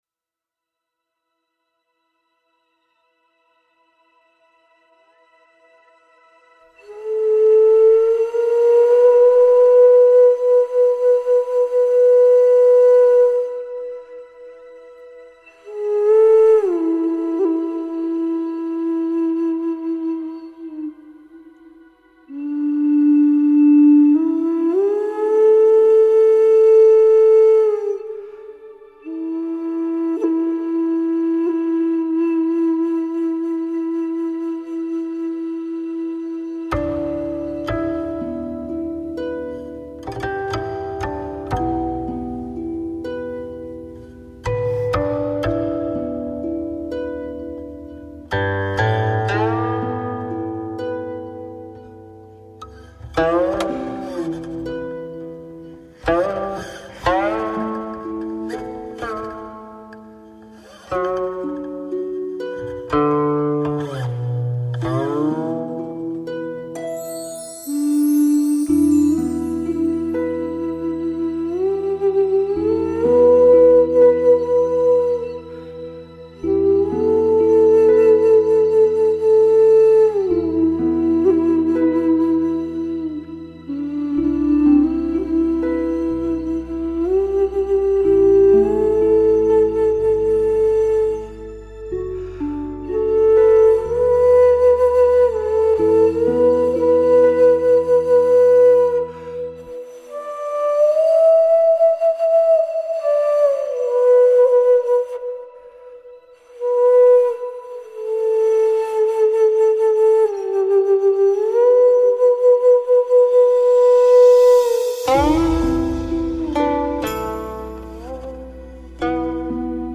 埙/箫/班苏里/哨笛/竖笛
中阮
古琴
大提琴
人声
东方禅意音乐
心无尘，淡若水，天籁寂语，琴韵清清，